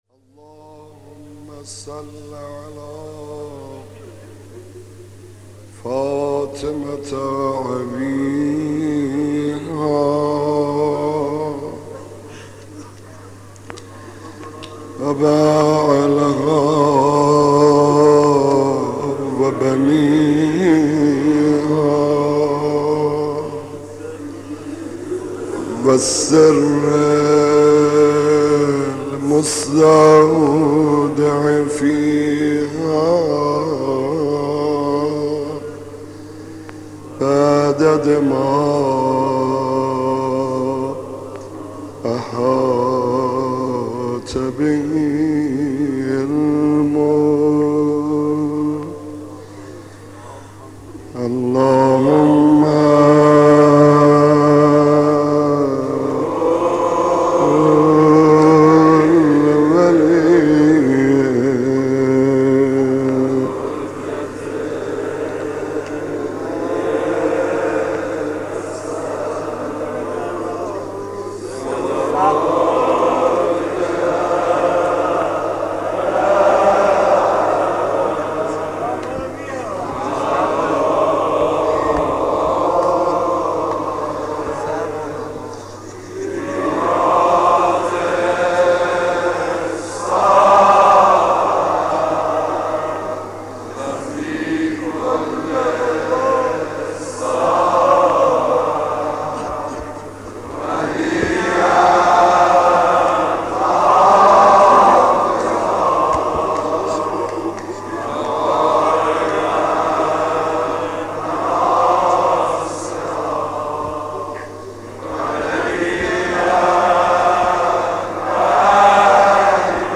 مداح
مناسبت : شام غریبان حسینی